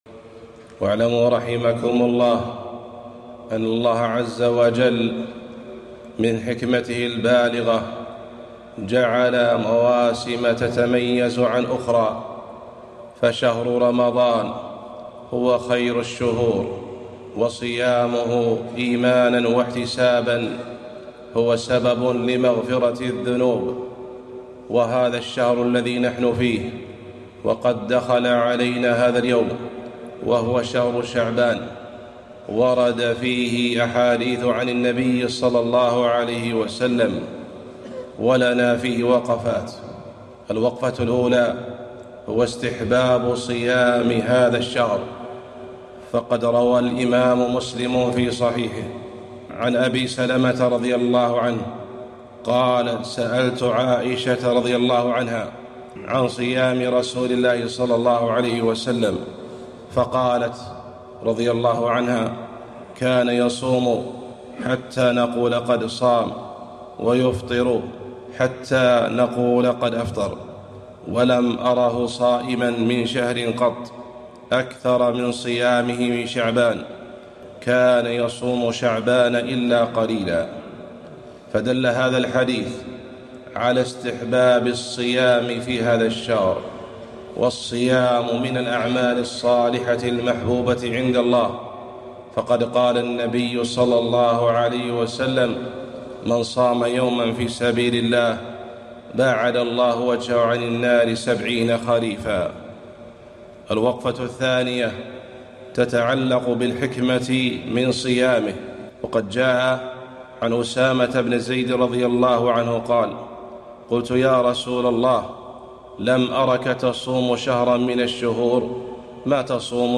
خطبة - وقفات مع شهر شعبان